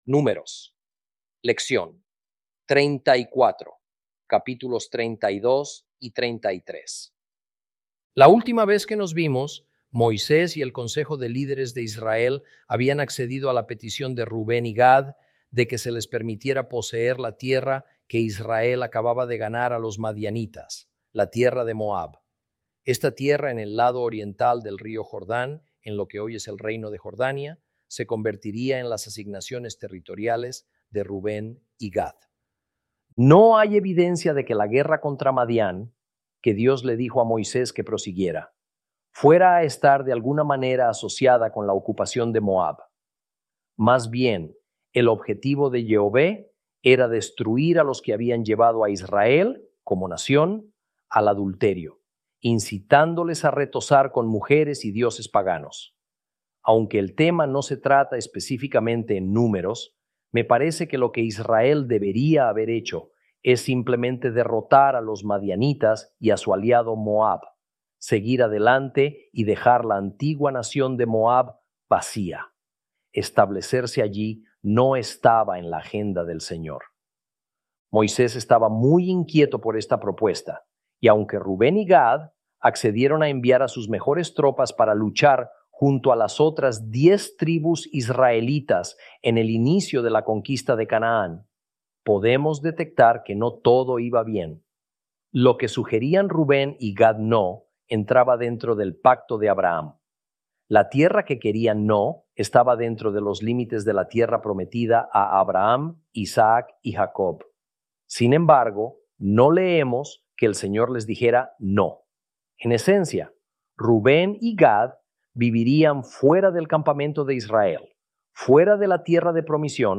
Números Lección 34 – Capítulos 32 y 33